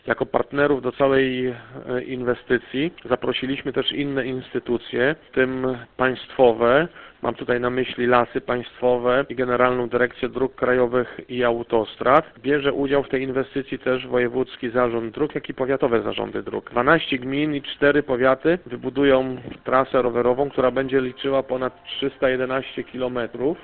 -Będzie biegła na terenie 12 samorządów, mówi Piotr Jakubowski, burmistrz Mikołajek i przewodniczący zarządu Stowarzyszenia Wielkie Jeziora Mazurskie 2020.